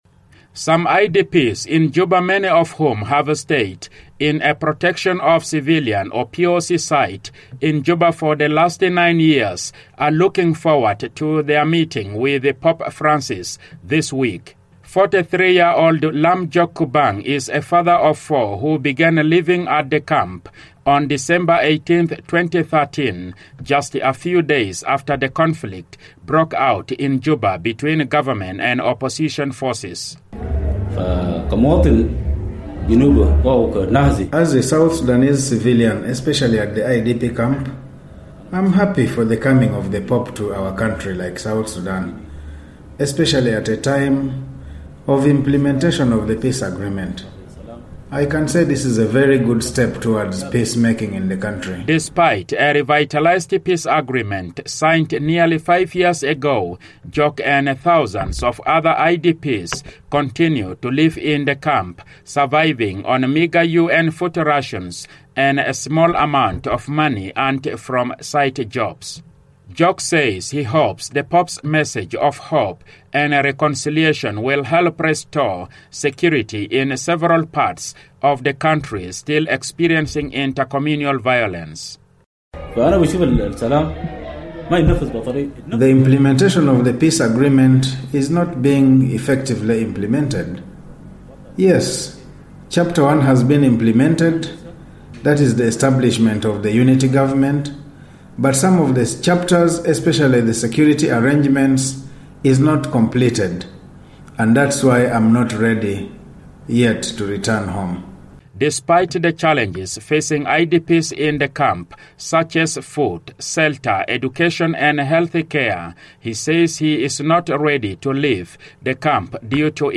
reports from Juba